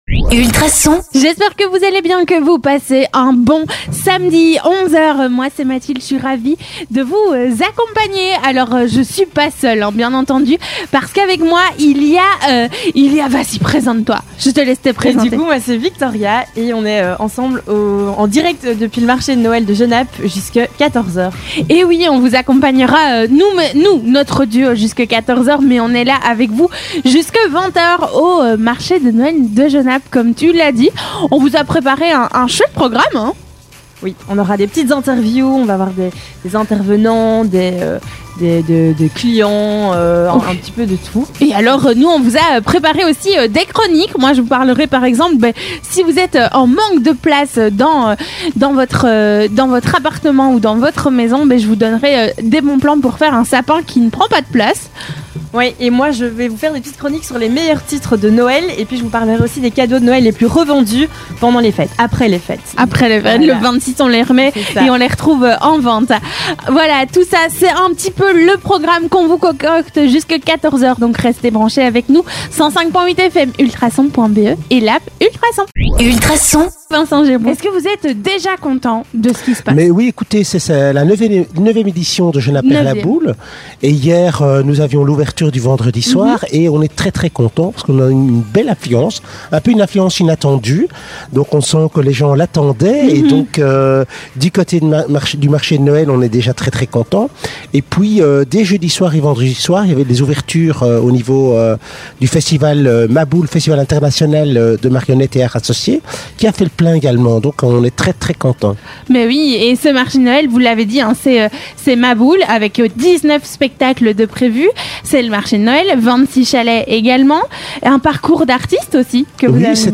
Gérard Couronné, Bourgmestre de Genappe